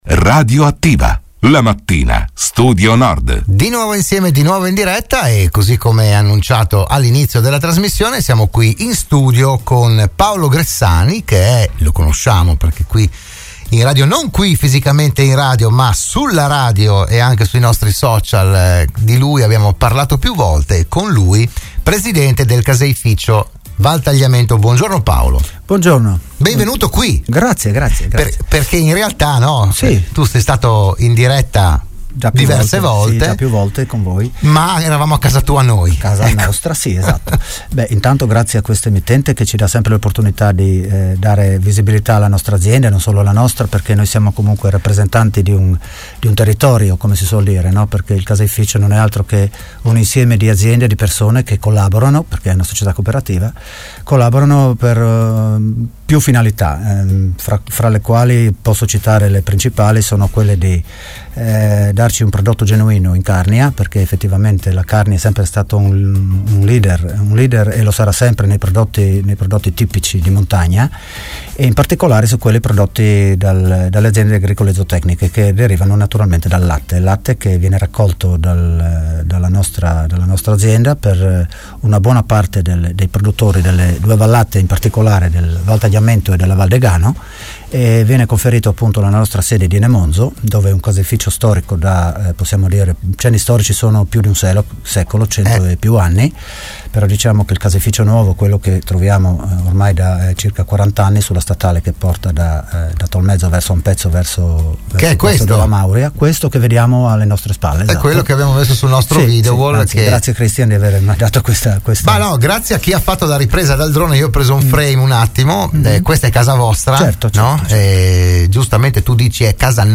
“RadioAttiva“, la trasmissione del mattino di Radio Studio Nord